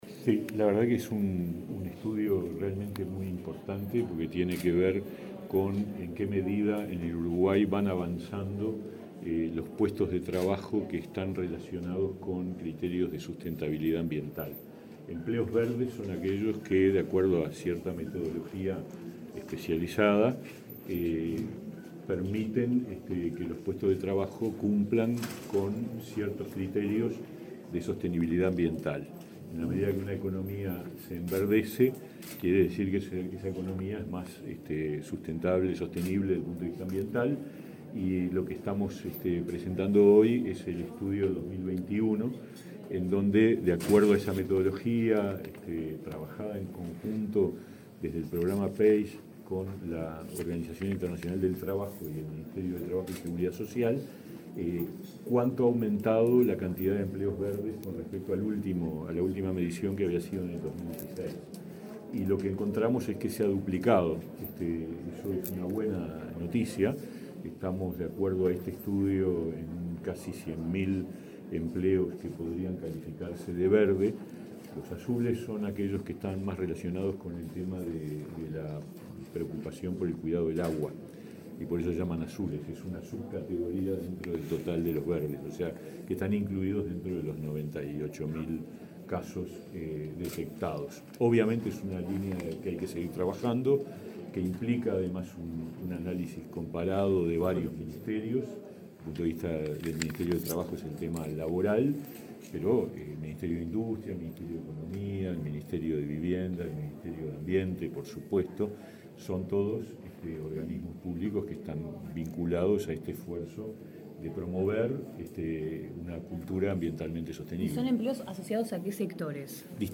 Declaraciones a la prensa del ministro de Trabajo, Pablo Mieres
Antes del acto, Mieres dialogó con la prensa.